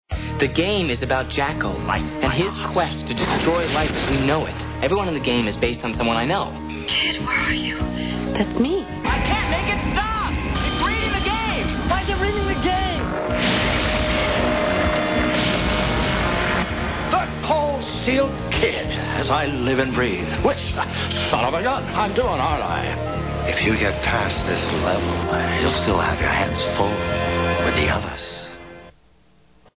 Intro used to start each episode.